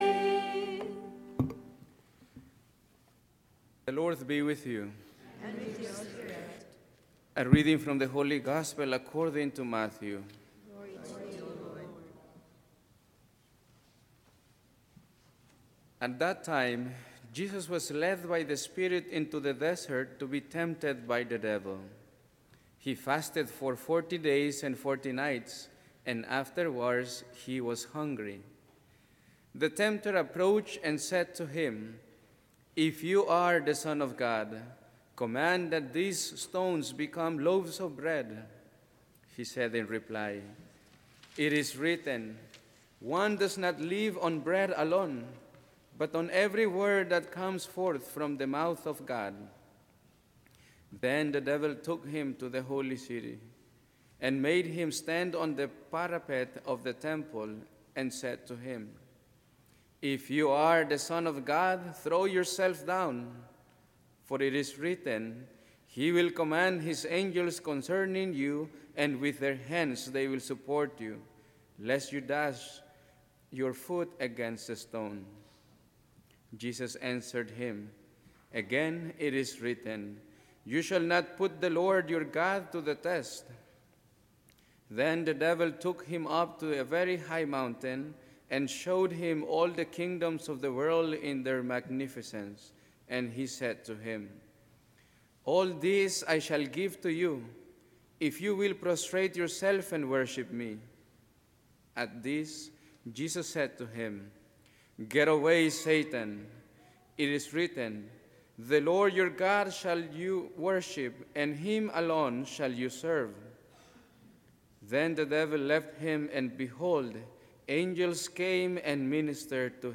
Homilist